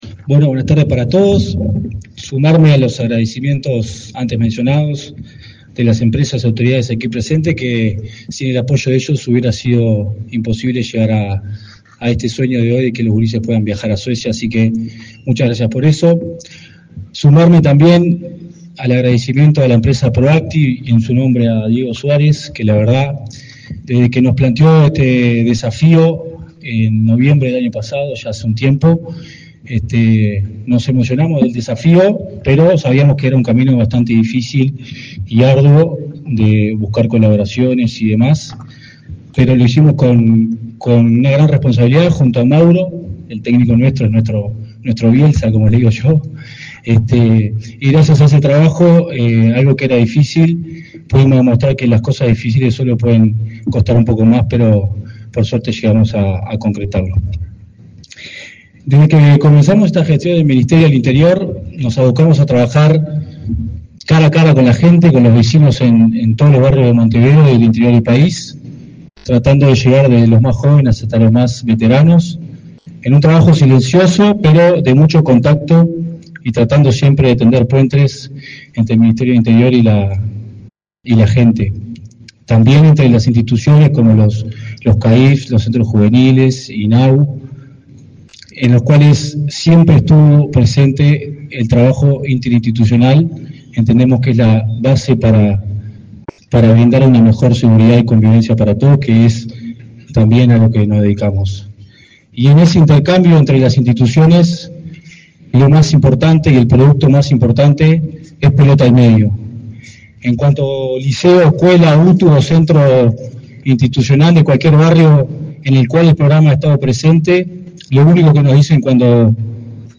Palabras del director de Convivencia y Seguridad Ciudadana del Ministerio del Interior, Matías Terra
Palabras del director de Convivencia y Seguridad Ciudadana del Ministerio del Interior, Matías Terra 04/07/2024 Compartir Facebook X Copiar enlace WhatsApp LinkedIn El ministro del Interior, Nicolás Martinelli, entregó, este 4 de julio, el pabellón nacional al grupo de competidores que participarán en la Gothia Cup, en Suecia. En el evento disertó el director de Convivencia y Seguridad Ciudadana, Matías Terra.